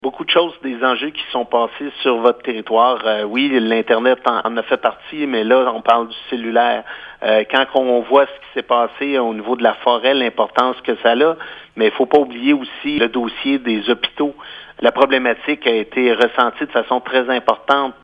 Le président explique que plusieurs enjeux touchent la région. Parmi ceux-ci, la couverture du réseau cellulaire, l’accès à l’internet haute vitesse et l’accès aux services de santé :